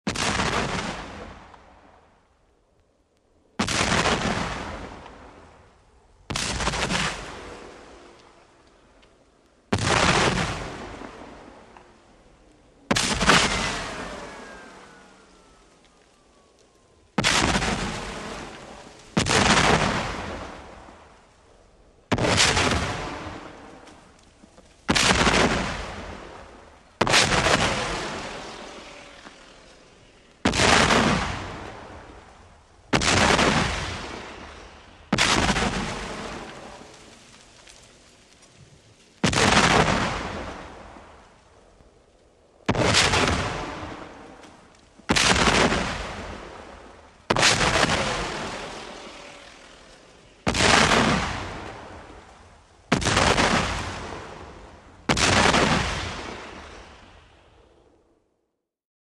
WEAPONS - CANNON CIVIL WAR CANNON: EXT: 10 lb. parrot cannons multiple shots.